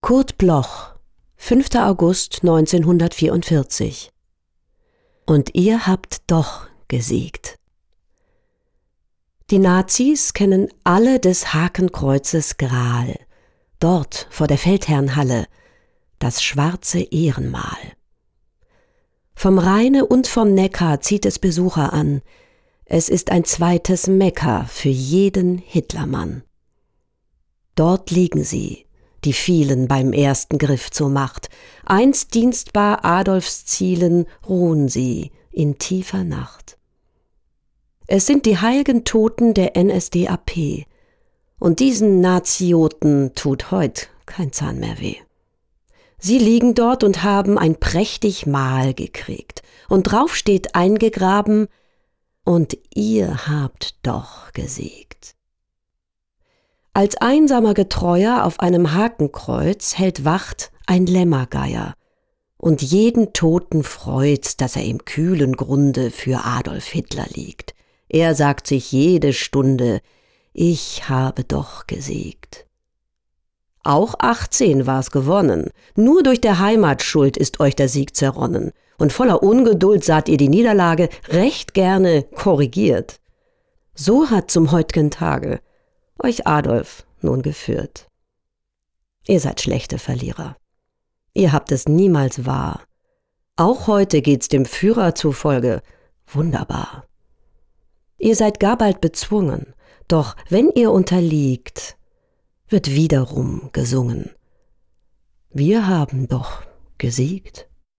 Recording: Killer Wave Studios, Hamburg · Editing: Kristen & Schmidt, Wiesbaden